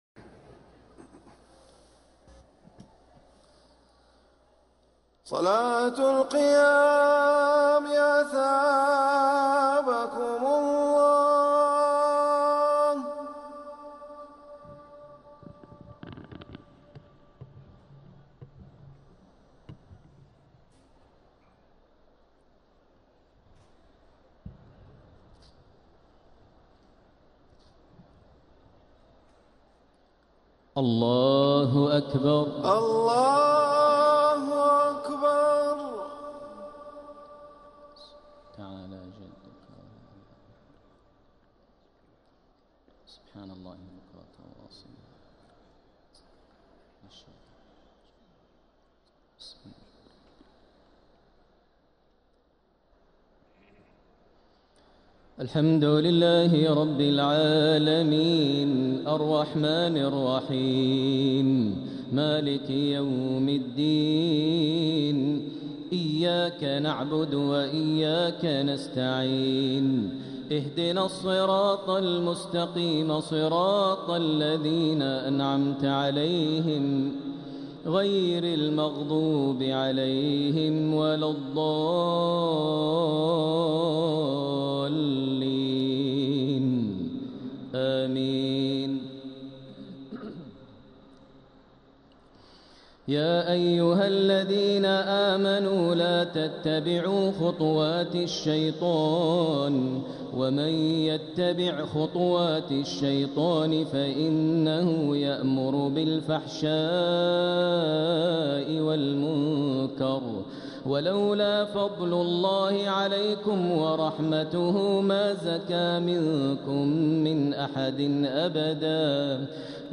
تهجد ليلة 22 رمضان 1446هـ من سورتي النور (21-64) و الفرقان (1-44) | Tahajjud 22nd night Ramadan1446H Surah An-Noor and Al-Furqan > تراويح الحرم المكي عام 1446 🕋 > التراويح - تلاوات الحرمين